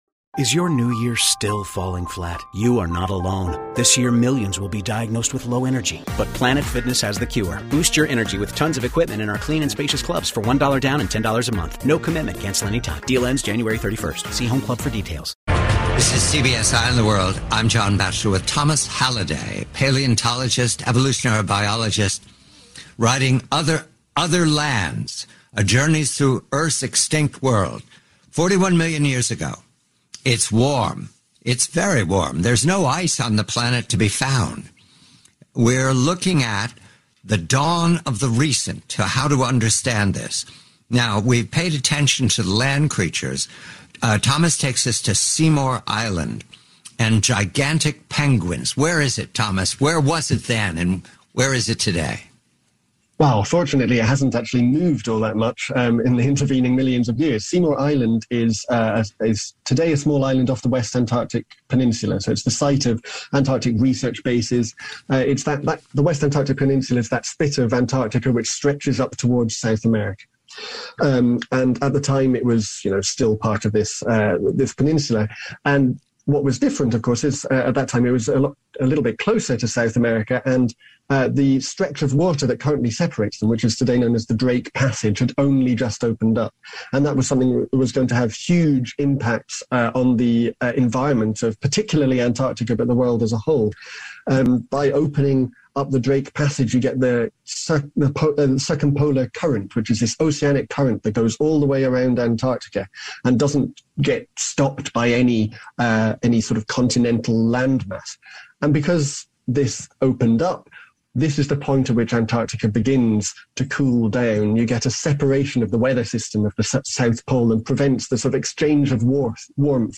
The conversation then shifts to the Paleocene following the K-Pg mass extinction .